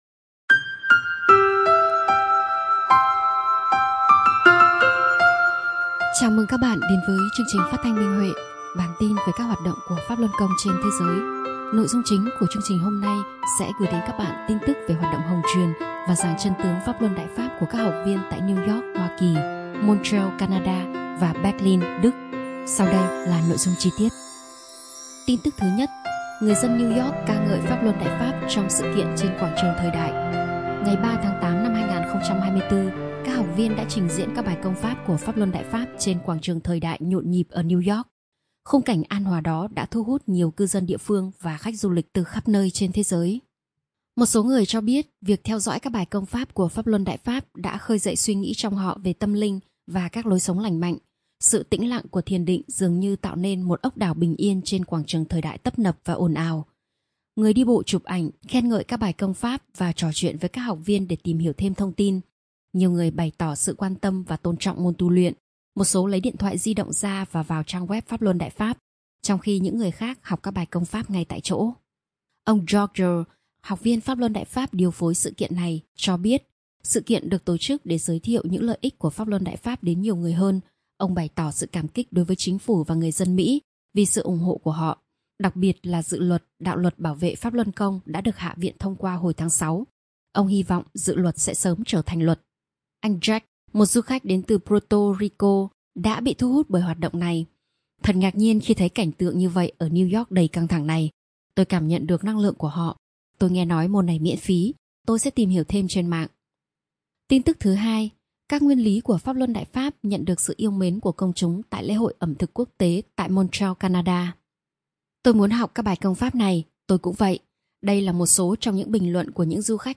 Chương trình phát thanh số 205: Tin tức Pháp Luân Đại Pháp trên thế giới – Ngày 12/8/2024